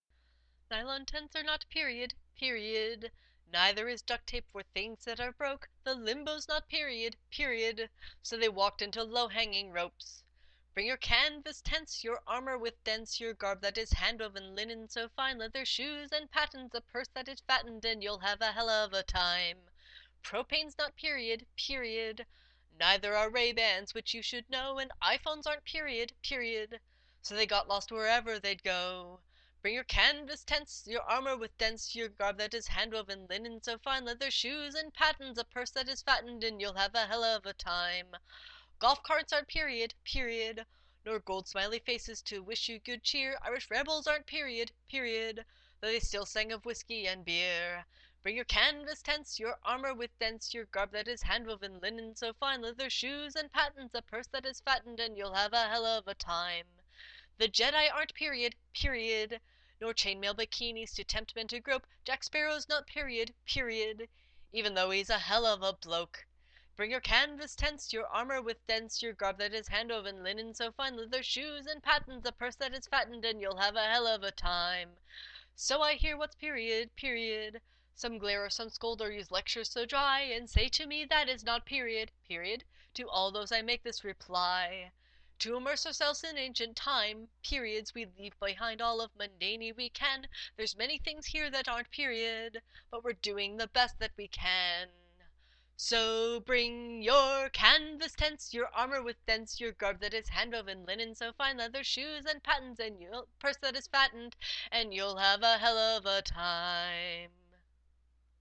A-Period-Song-Rough-Cut.mp3